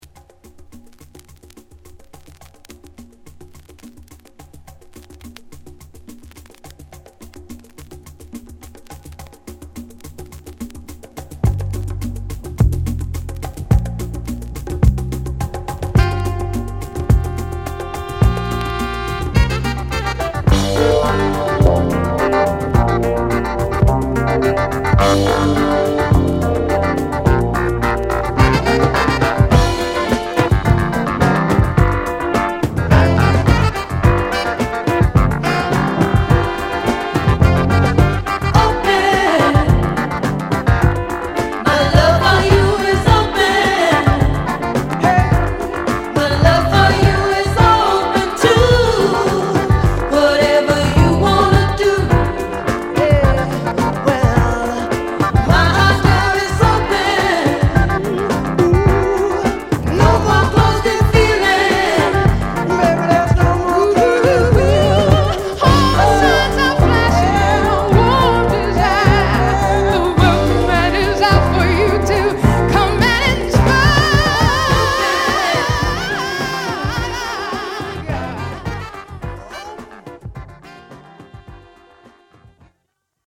リズムマシンにクラヴィやホーンが絡むファンキーチューン！